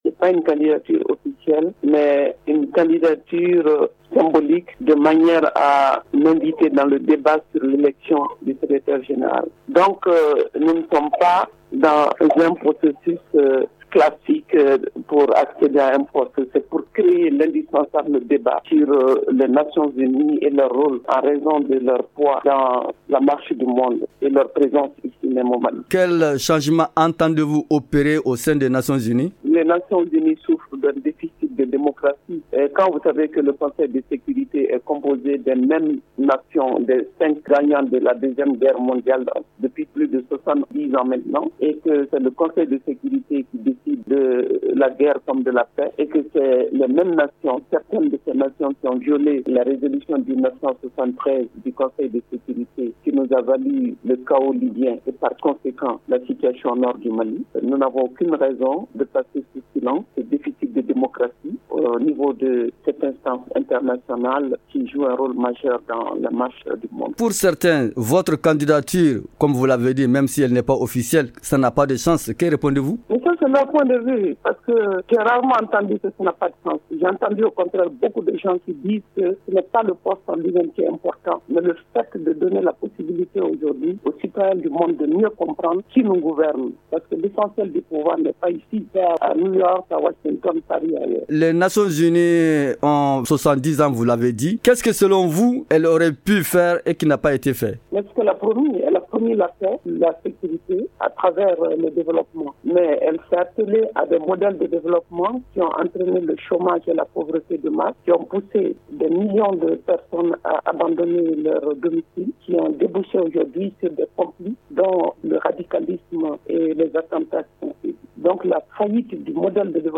Aminata Dramane Traoré répond aux questions